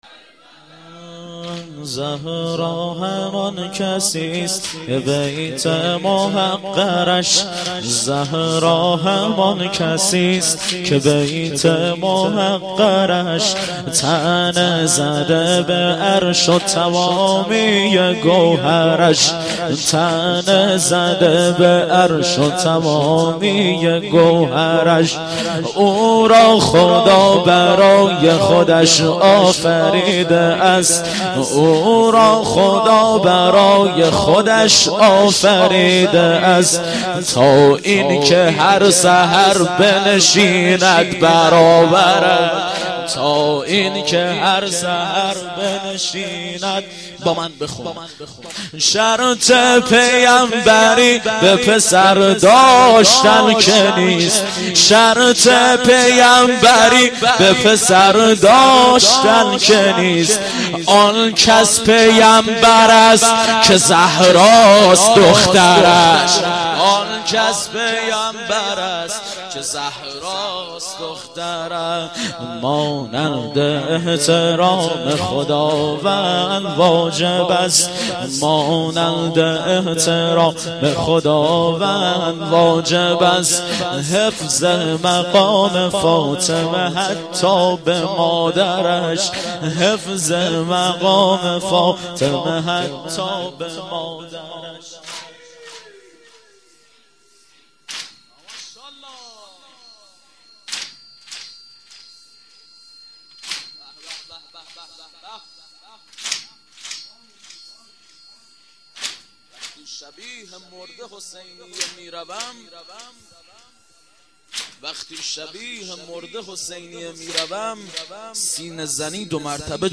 واحد - زهرا همان کسی است - مداح